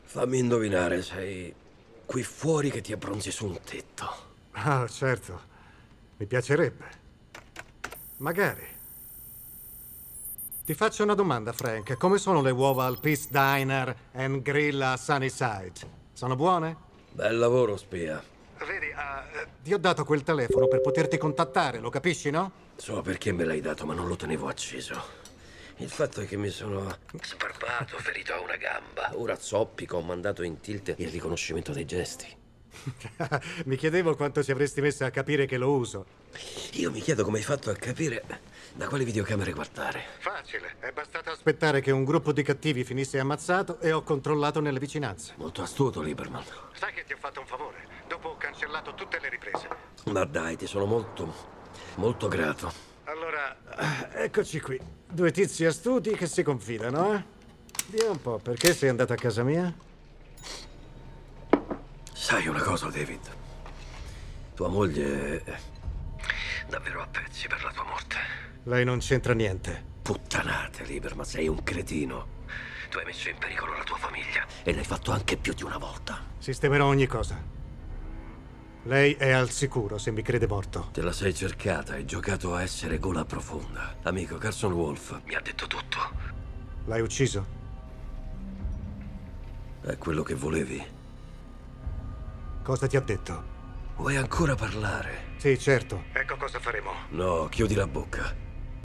nel telefilm "The Punisher", in cui doppia Ebon Moss-Bachrach.